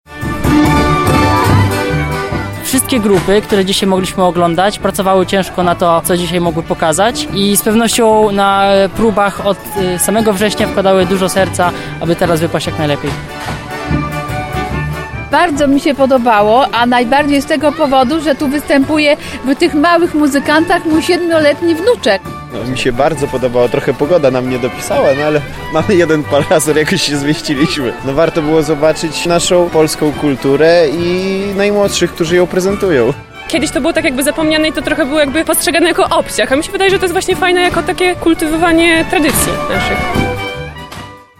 Wszystkie trzy dni pokazów miały miejsce w muszli koncertowej w Ogrodzie Saskim.